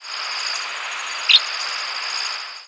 Tree Swallow diurnal flight calls
Fig.2. New Jersey August 5, 2001 (MO).
"Chi-deep" call from bird in flight.